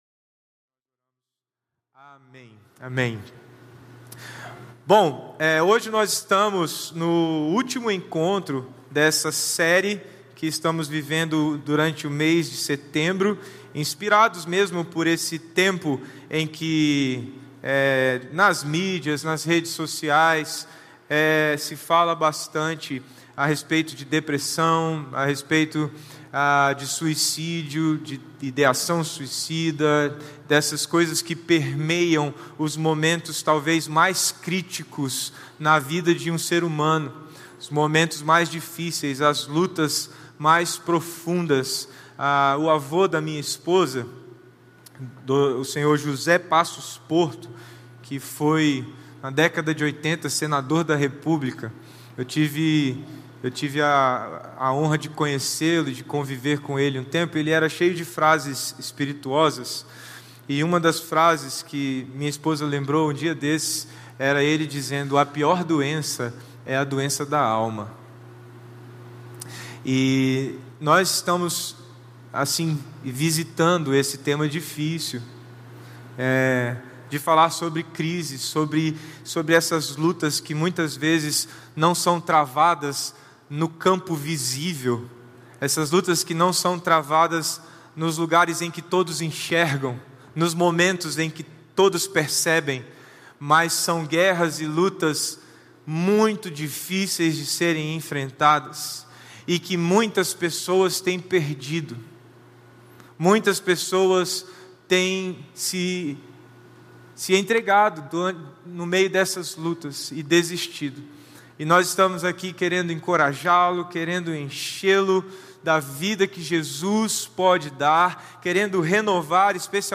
Mensagem apresentada por Igreja Batista Capital como parte da série Crise na Igreja Batista Capital.